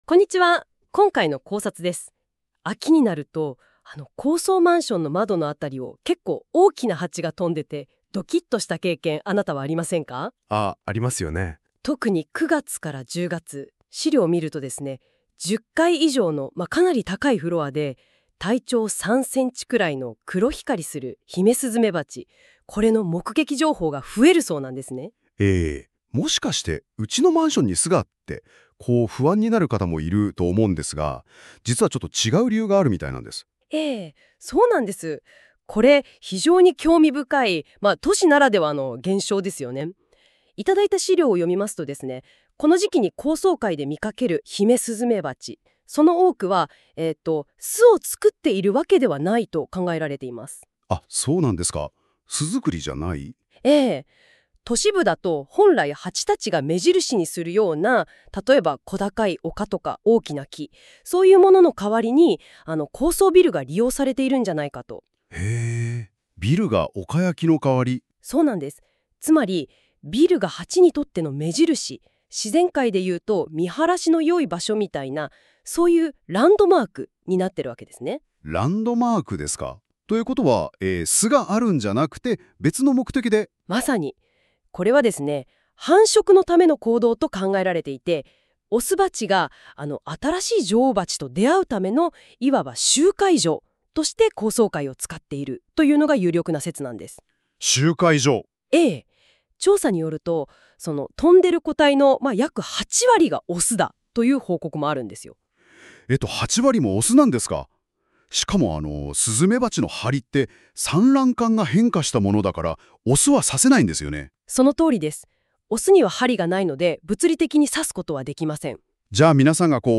🎧 音声解説（約7分）